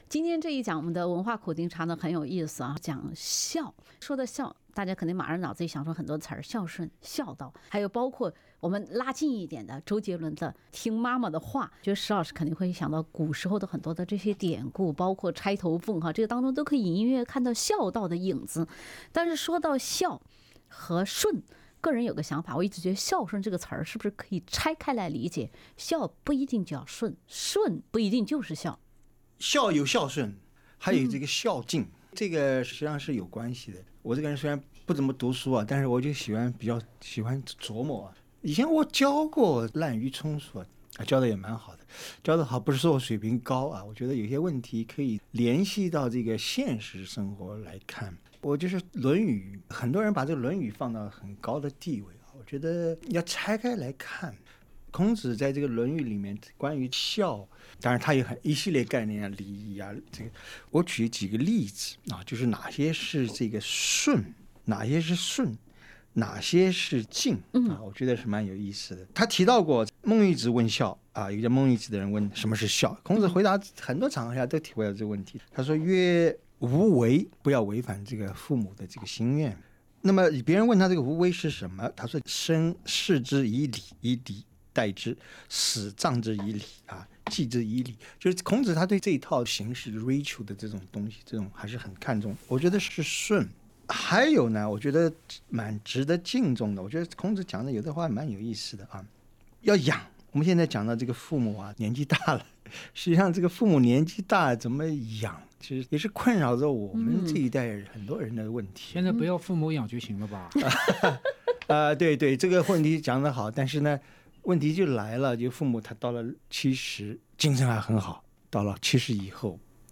欢迎收听SBS 文化时评栏目《文化苦丁茶》，本期话题是：“孝”源于土地（全集）。